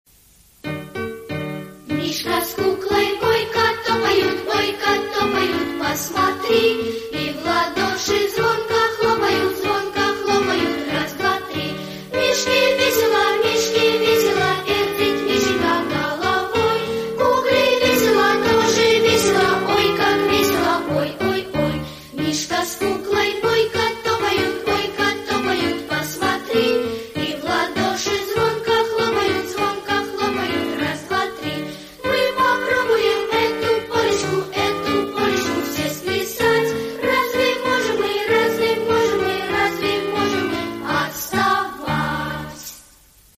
Мишка с куклой пляшут полечку - песенка с движениями - слушать онлайн